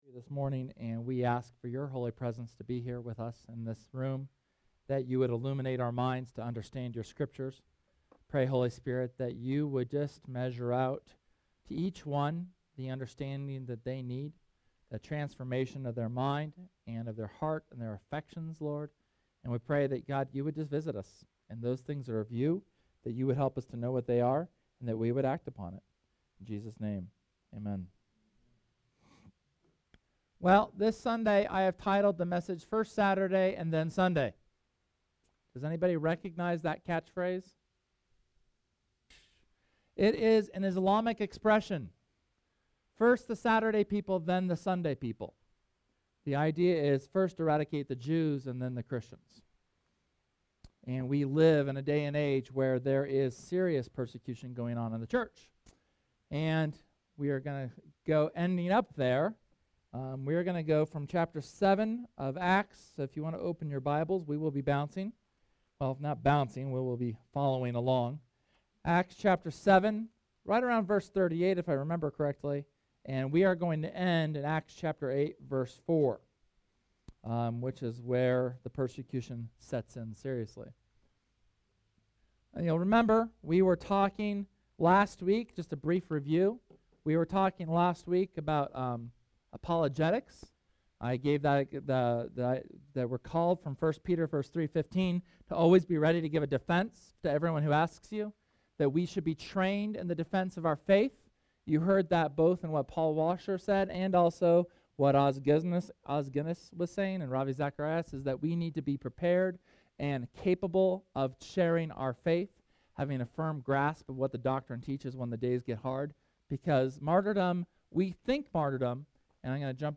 Sermon from Sunday, June 2nd on the second half of Acts chapter 8 with a discussion of modern persecution of Christians.